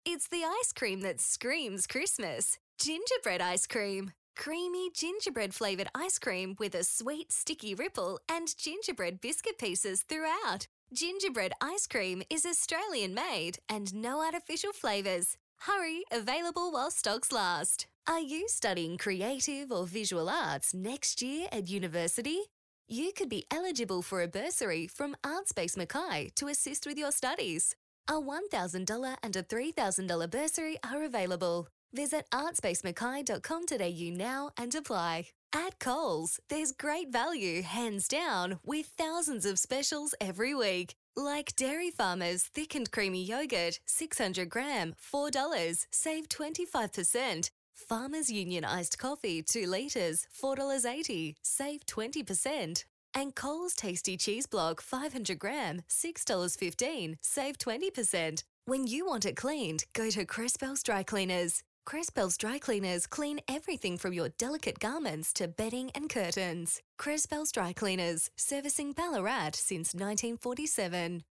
• Retail Friendly
• Young
• Bright
• Fresh & Friendly
• Neumann TLM 103
• Own Home Studio